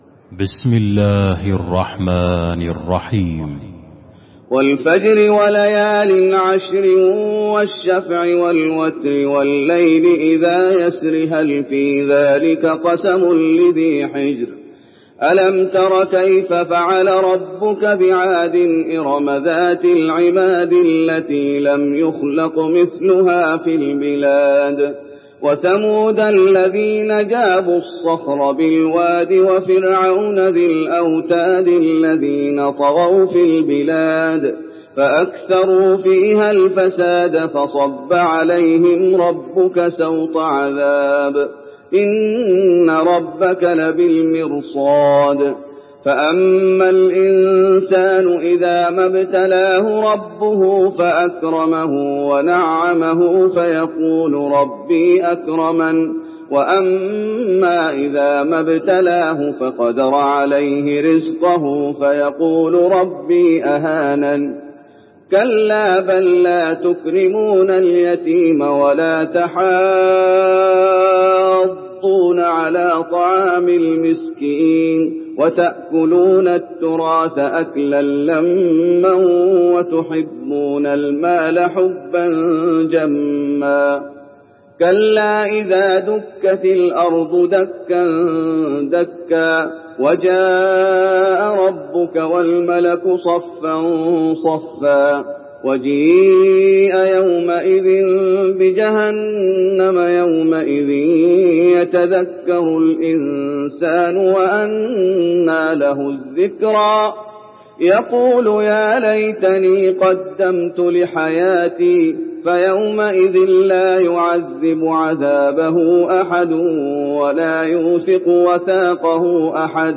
المكان: المسجد النبوي الفجر